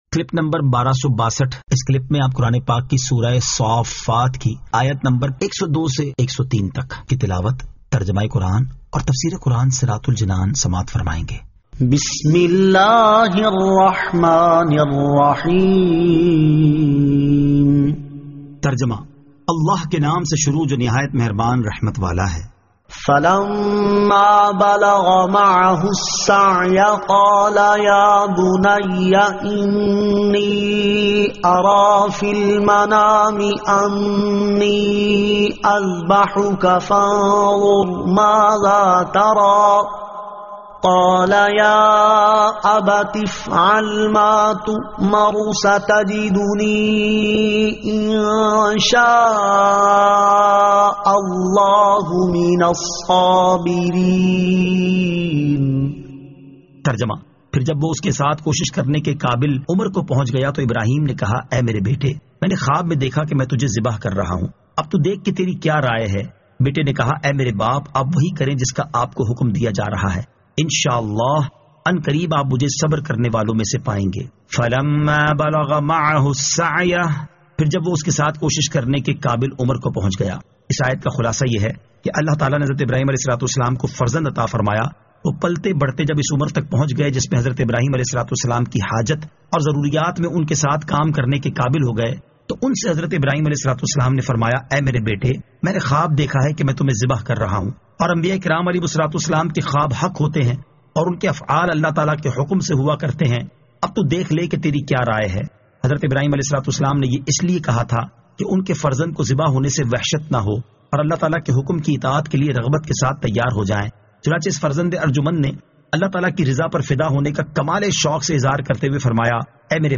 Surah As-Saaffat 102 To 103 Tilawat , Tarjama , Tafseer
2023 MP3 MP4 MP4 Share سُوَّرۃُ الصَّافَّات آیت 102 تا 103 تلاوت ، ترجمہ ، تفسیر ۔